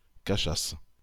-Caixas.wav Audio pronunciation file from the Lingua Libre project.